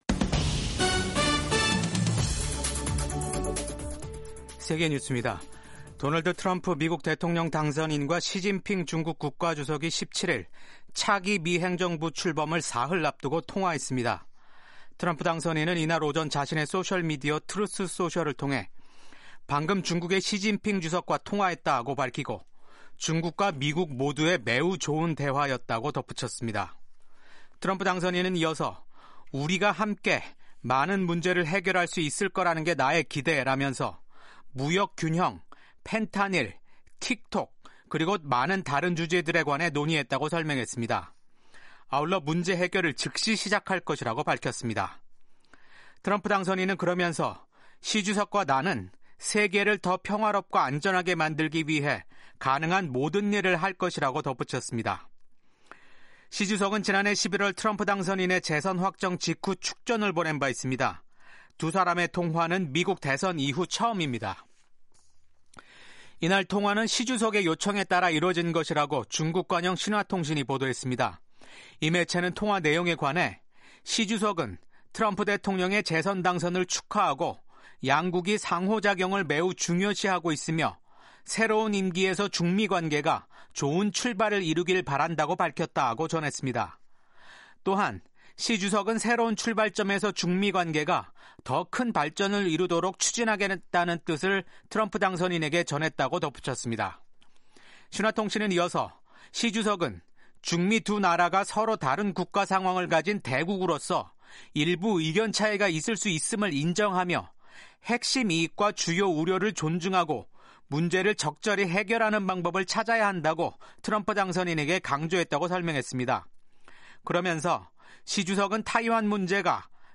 세계 뉴스와 함께 미국의 모든 것을 소개하는 '생방송 여기는 워싱턴입니다', 2025년 1월 18일 아침 방송입니다. 이스라엘과 하마스와의 휴전∙인질 석방 협상이 타결됐다고 이스라엘 총리가 밝혔습니다. 미국에서 소셜미디어 틱톡 금지법이 곧 발효되는 가운데 도널드 트럼프 차기 행정부가 이를 막을 방안을 마련할 것임을 시사했습니다. 퇴임을 앞둔 조 바이든 미국 대통령이 고별 연설을 통해 소수 부유층의 권력 집중을 경고했습니다.